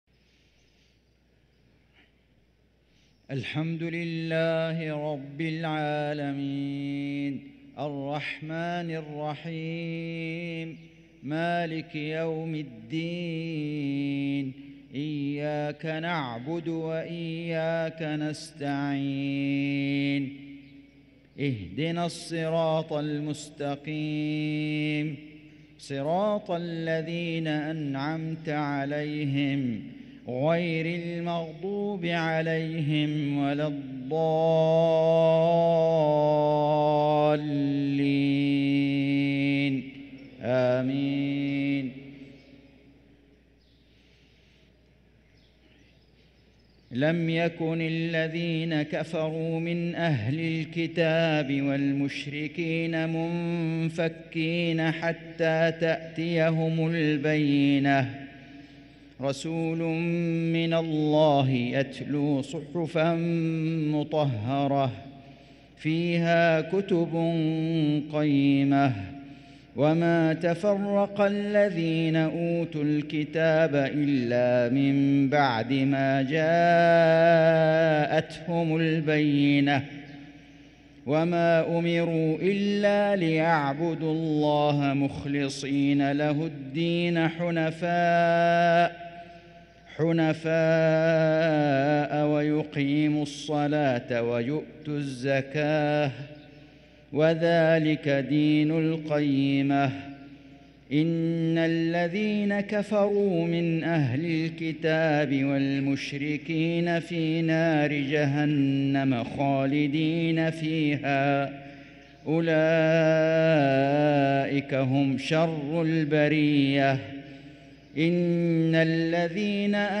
صلاة المغرب ٩-٨-١٤٤٤هـ من سورة البينة والقارعة | Maghrib prayer from Surah al-Bayyinah & al-Qari`ah 1-3-2023 > 1444 🕋 > الفروض - تلاوات الحرمين